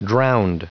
Prononciation du mot drowned en anglais (fichier audio)
Prononciation du mot : drowned